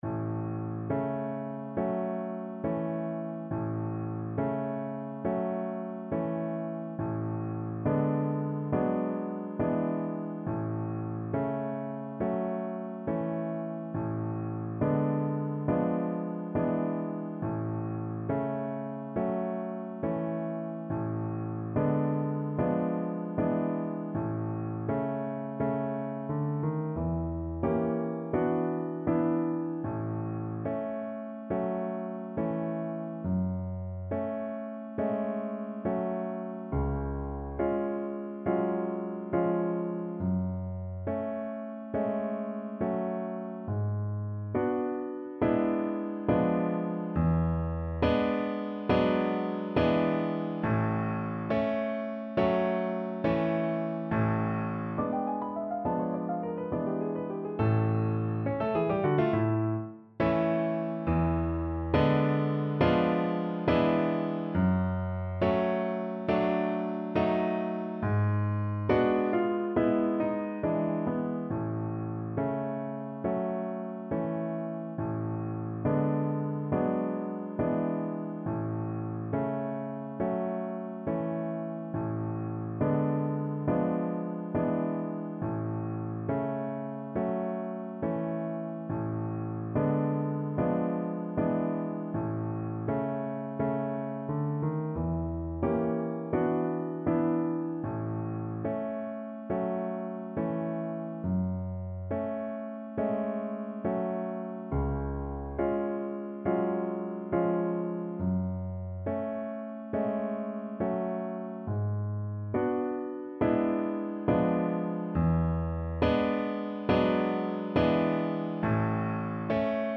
A major (Sounding Pitch) (View more A major Music for Violin )
Andante non troppo con grazia =69
4/4 (View more 4/4 Music)
Classical (View more Classical Violin Music)